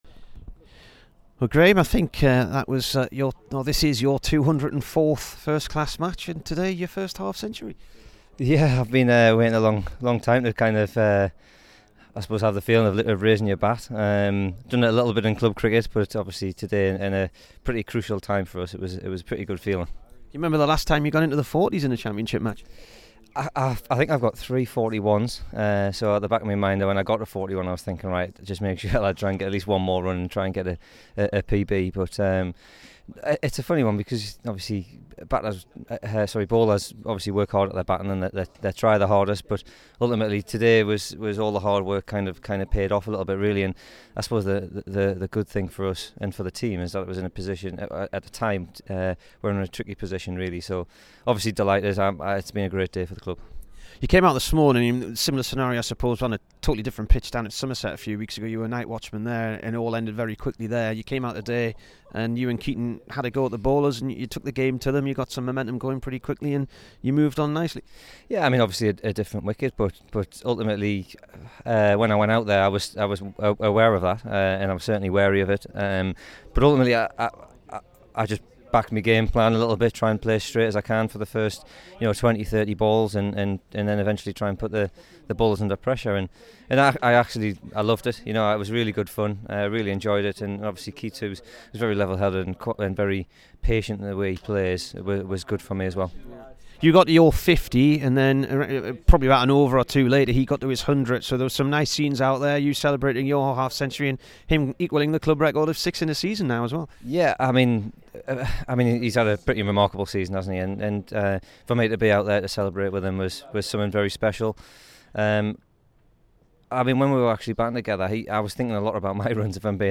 Here is the Durham bowler after a career best 65 runs v Notts.